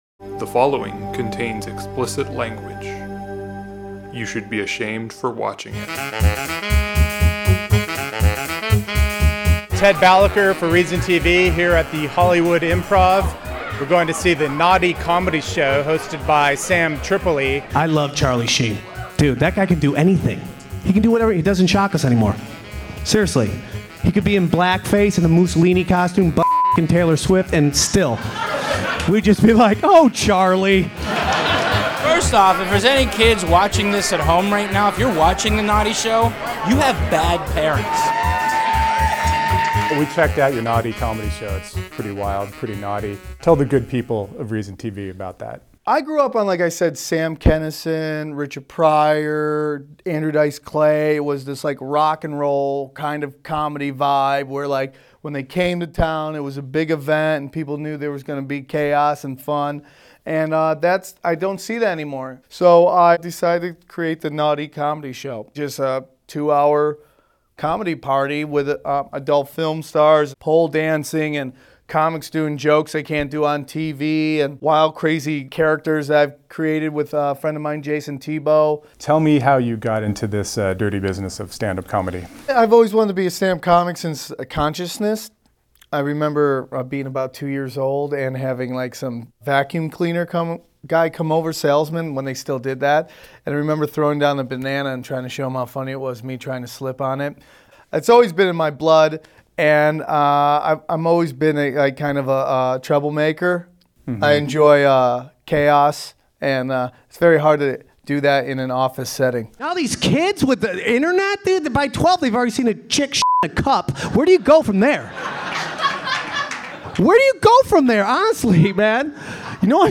This video contains explicit content, and viewer discretion is advised.